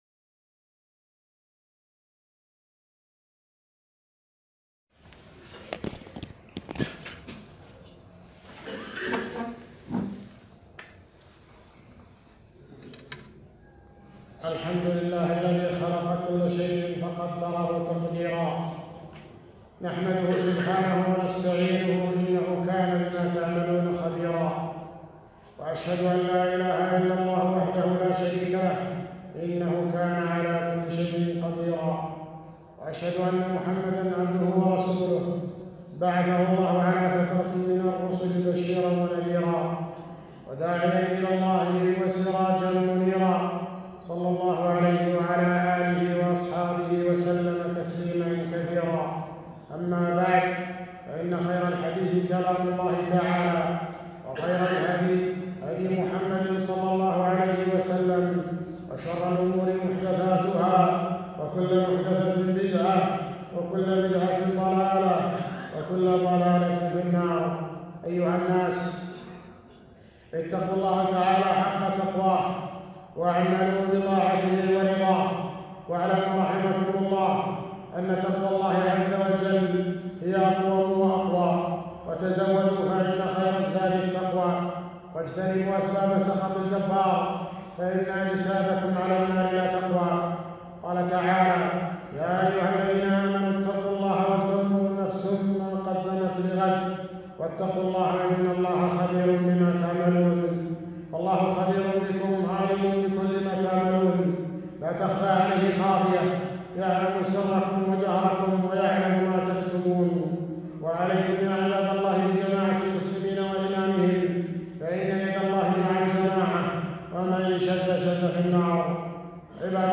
خطبة - الإيمان بالقدر